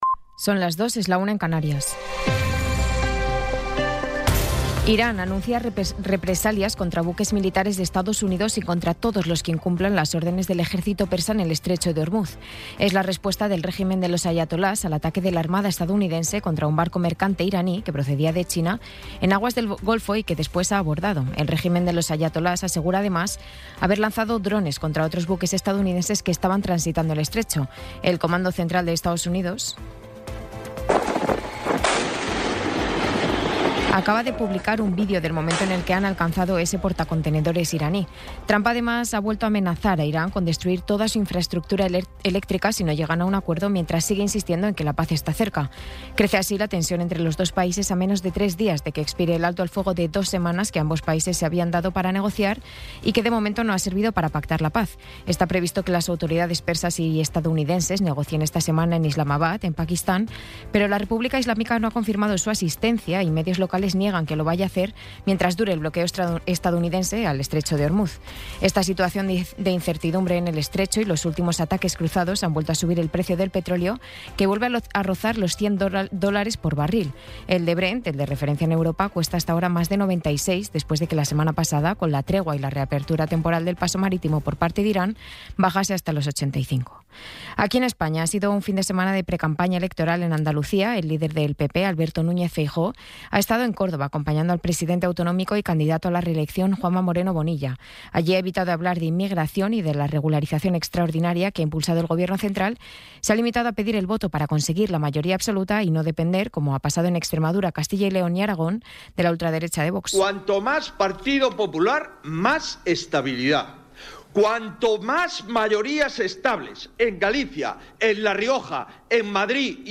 Resumen informativo con las noticias más destacadas del 20 de abril de 2026 a las dos de la mañana.